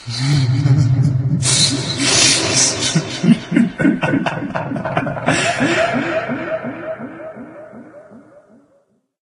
smeh.mp3